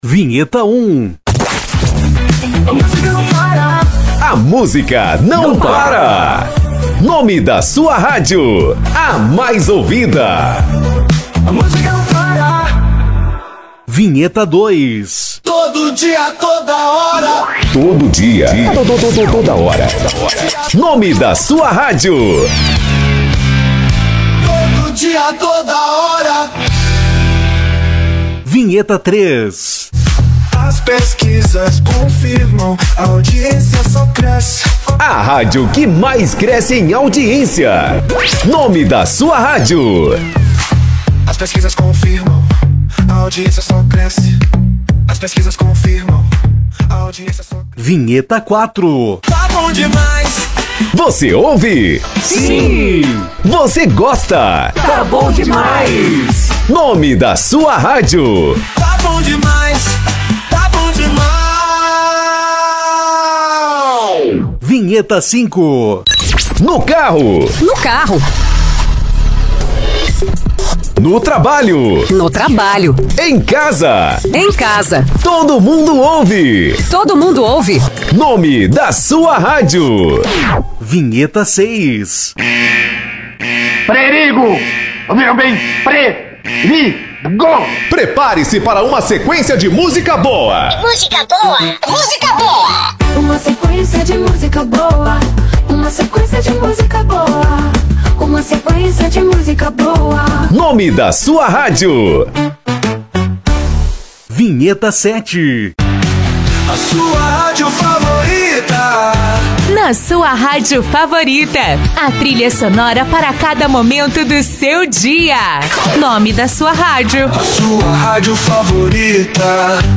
– Diversos estilos para atender todas as suas necessidades
– Vinhetas de alta qualidade, prontas para uso!